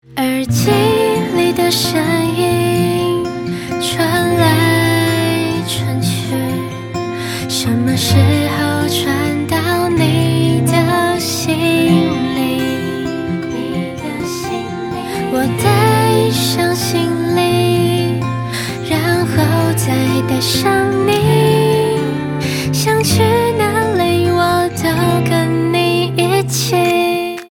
Pop folk, Chinese pop